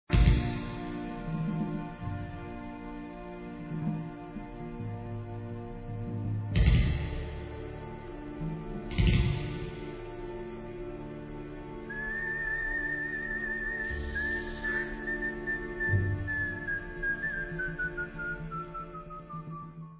Spaghetti western magic from the meastro